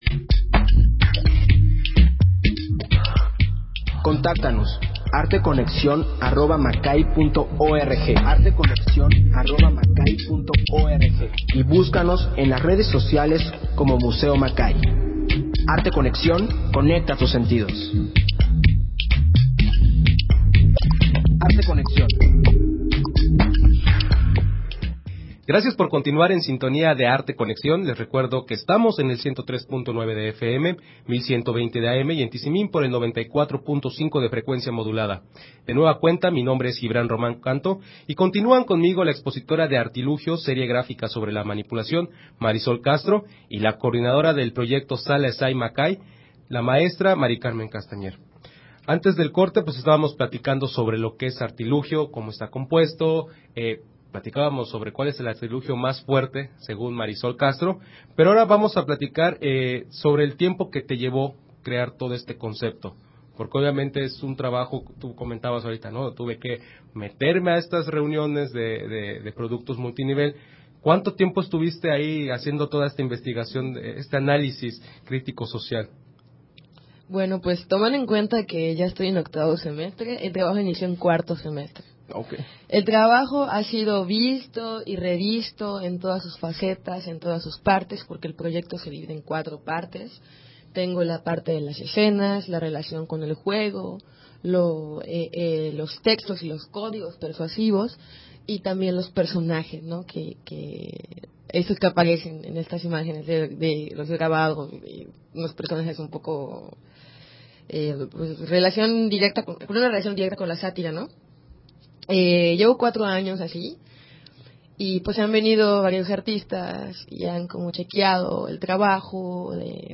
Emisión de Arte Conexión transmitida el 2 de marzo del 2017.